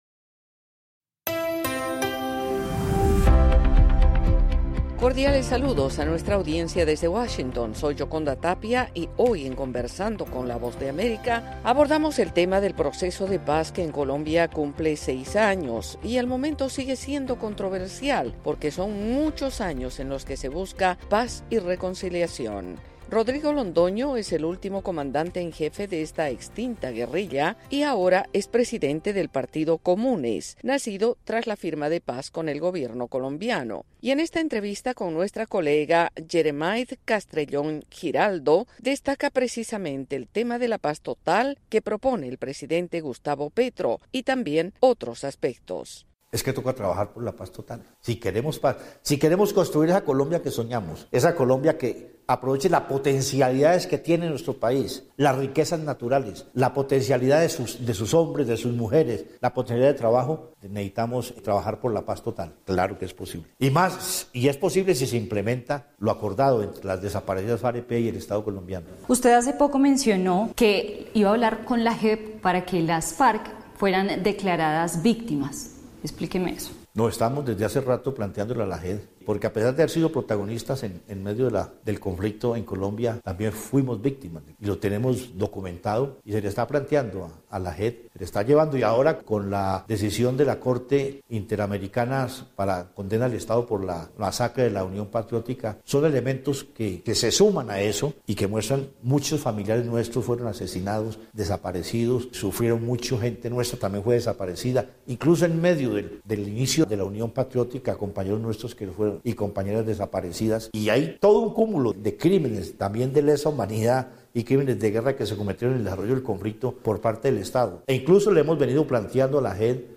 Conversamos con Rodrigo Londoño, excomandante en jefe de la desaparecida guerrilla de las FARC hablando sobre un futuro de paz para Colombia.